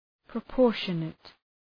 Προφορά
{prə’pɔ:rʃənət} (Επίθετο) ● ανάλογος